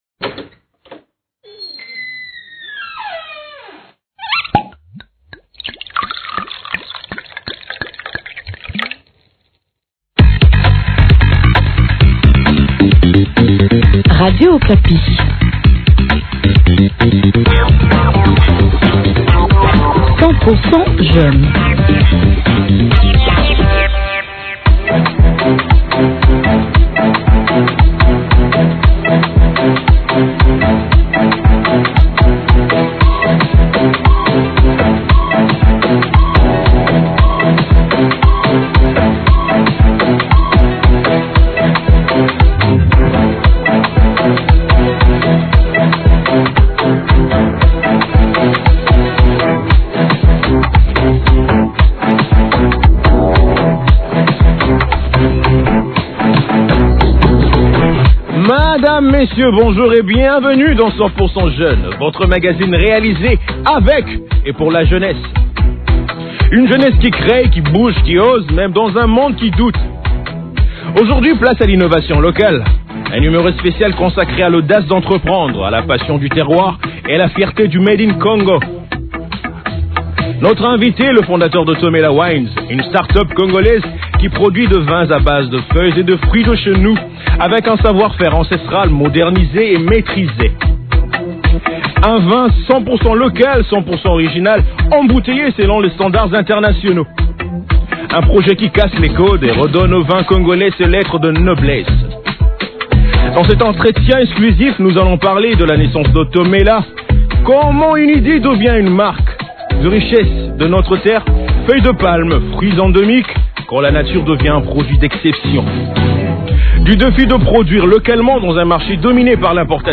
Dans cet entretien exclusif, nous allons parler : De la naissance de TOMELA : comment une idée devient une marque.